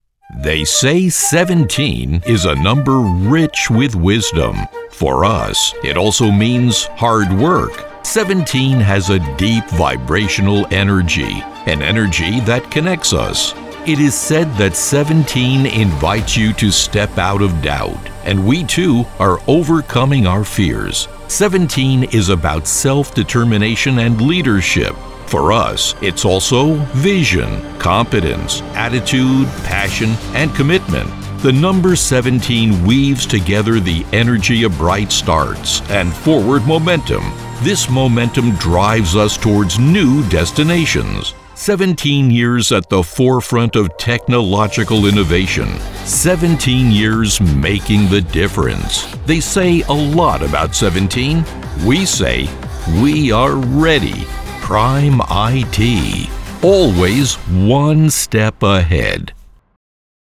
Comercial, Profundo, Natural, Llamativo, Amable
Corporativo